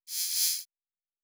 pgs/Assets/Audio/Sci-Fi Sounds/Interface/Data 22.wav at master